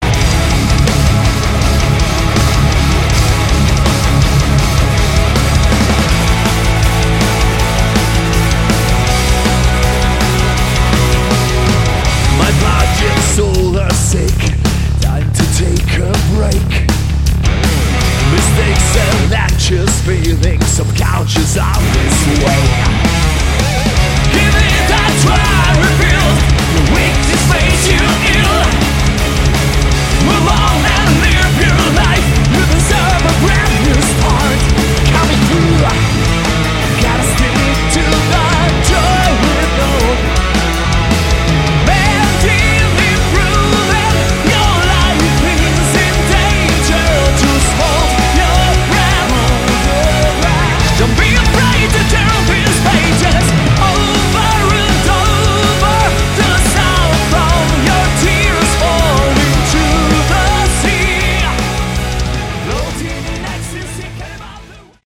Category: Melodic Metal
vocals
guitar
bass
backing vocals
keyboards
drums